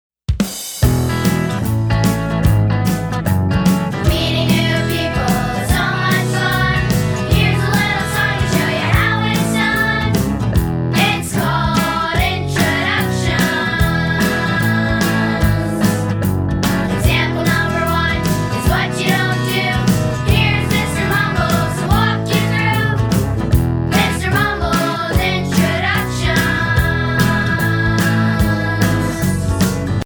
Fun, sing-a-long music for home or classroom.